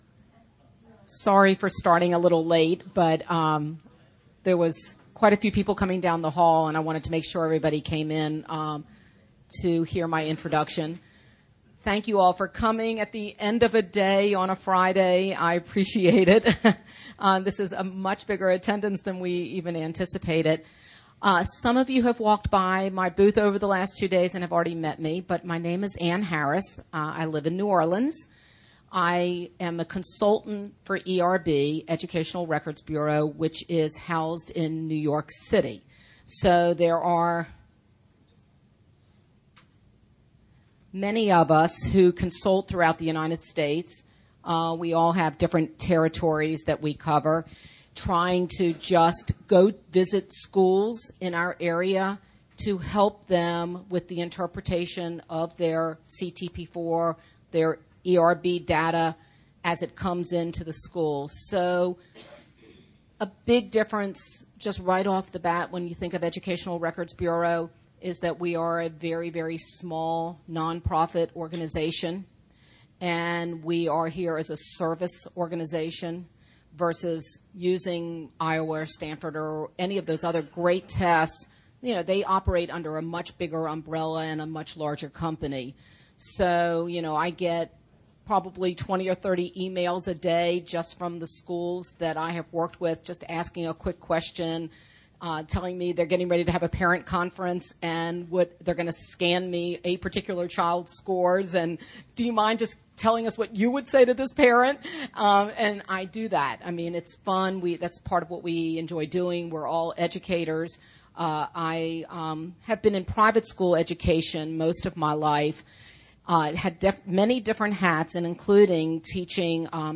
2008 Workshop Talk | 1:04:43 | All Grade Levels, Leadership & Strategic